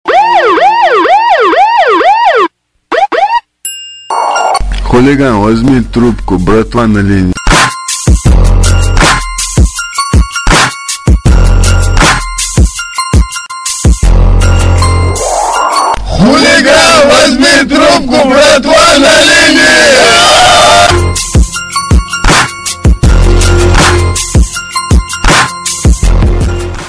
громкие
голосовые
Сирена